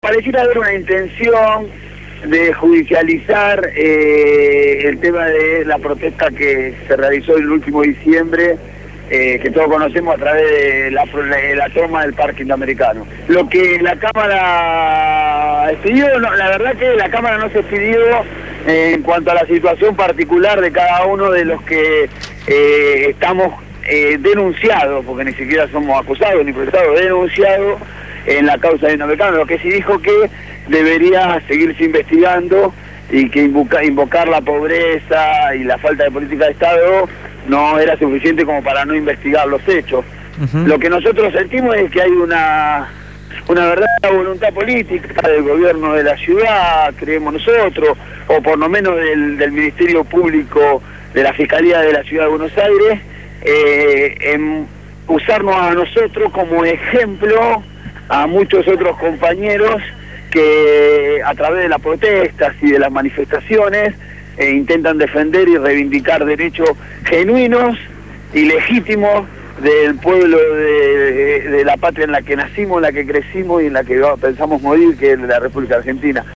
En conversación telefónica